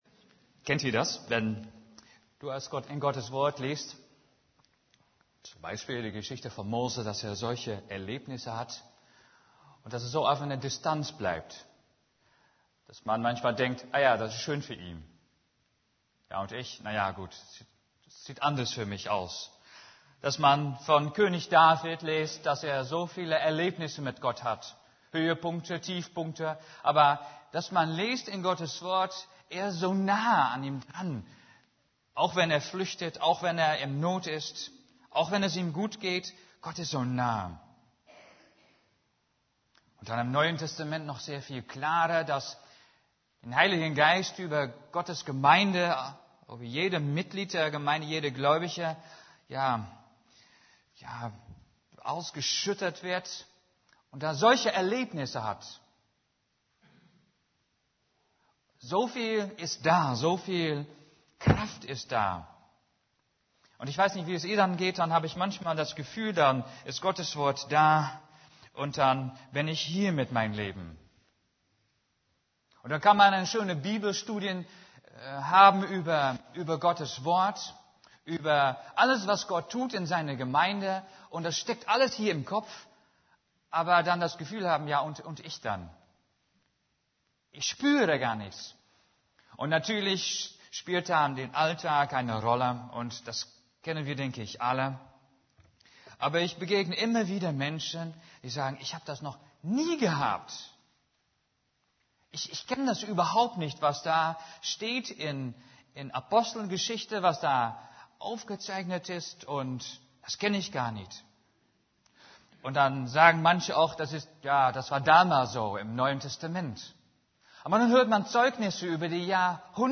> Übersicht Predigten Die Kraft des Heiligen Geistes neu entdecken Predigt vom 10.